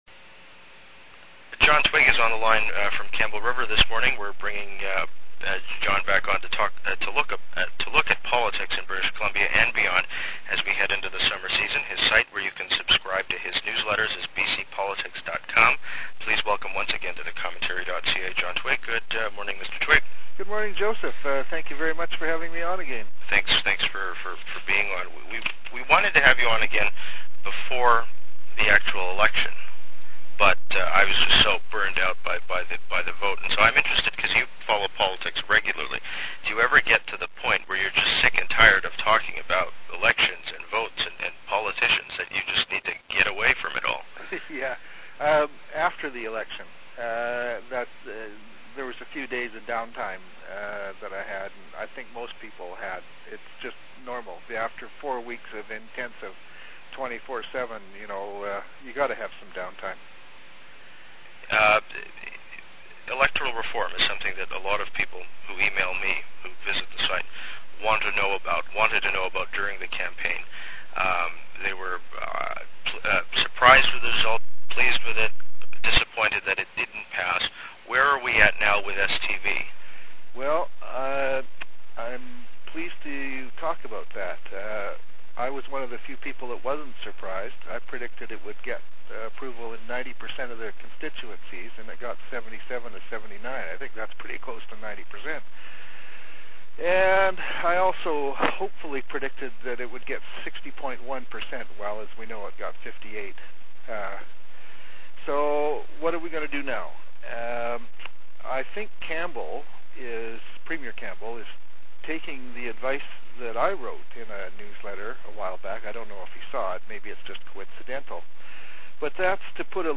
Independent journalist
is on the line from Campbell River this morning.